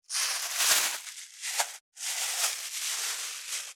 608ゴミ袋,スーパーの袋,袋,買い出しの音,
効果音